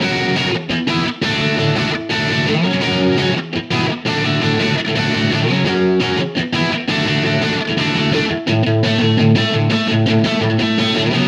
Indie Pop Guitar 01.wav